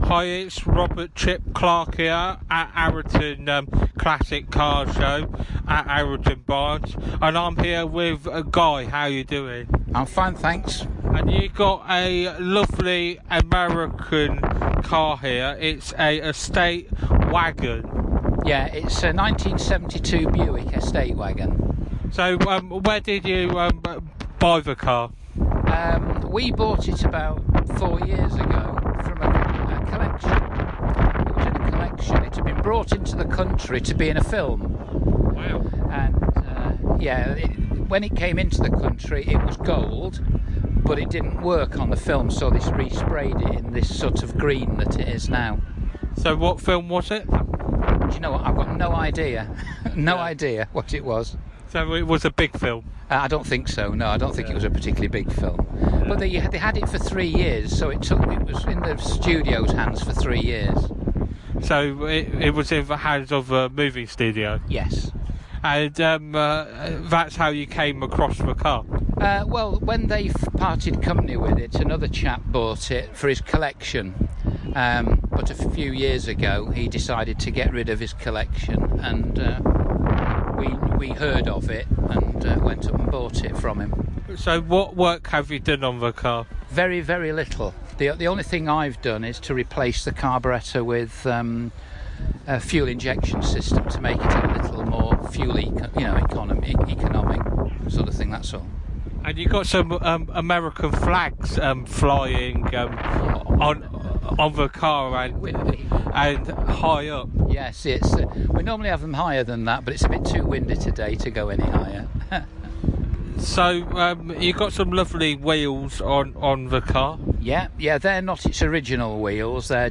Arreton Classic Car Show
Interview